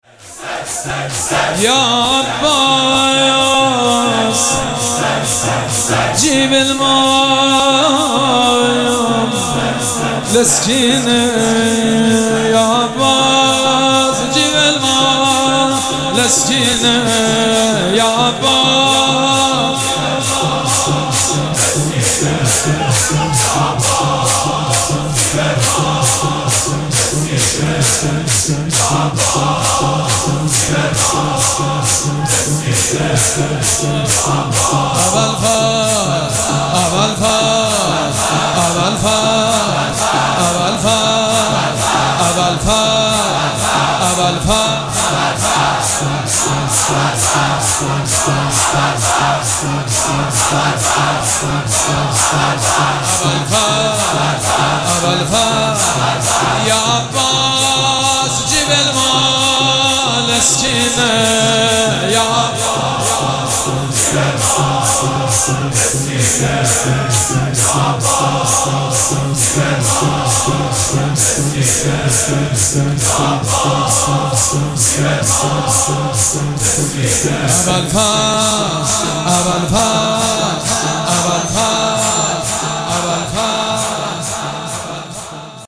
مراسم عزاداری شب نهم محرم الحرام ۱۴۴۷
شور
مداح